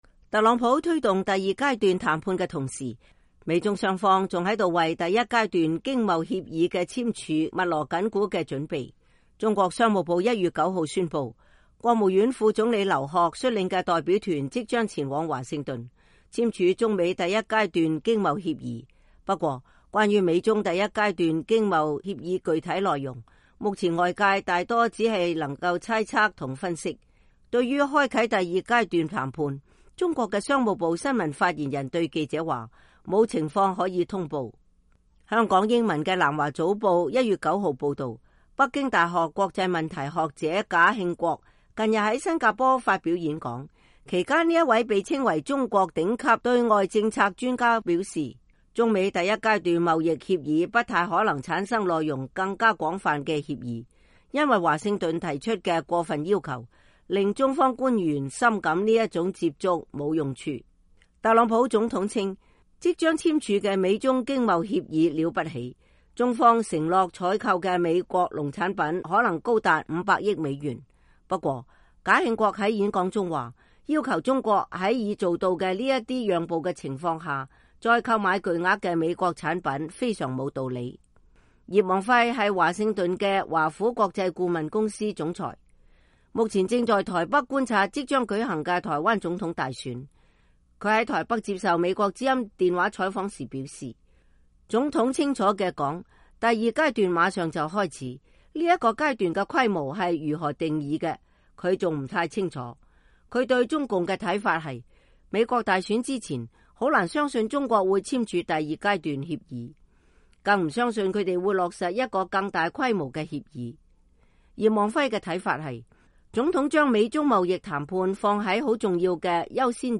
他在台北接受美國之音電話採訪時表示：“總統清楚地說，第二階段馬上就開始，這個階段的規模是如何定義的，我還不太清楚。